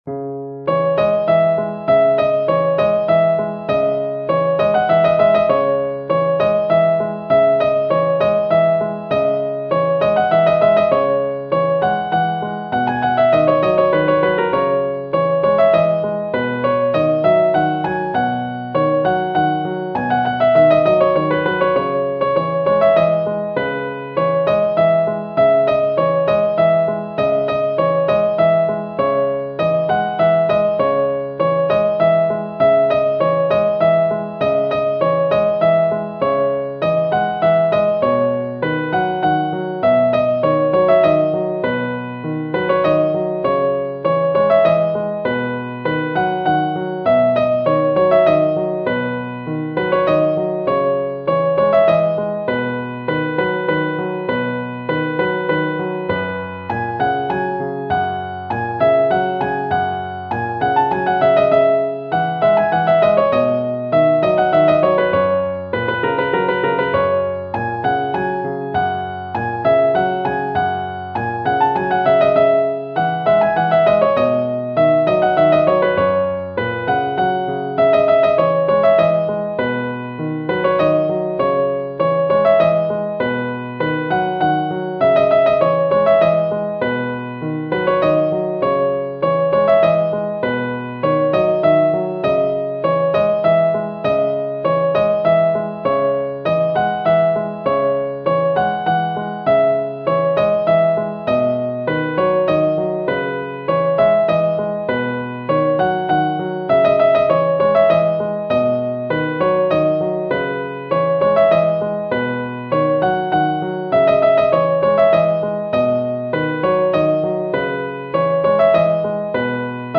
ساز : پیانو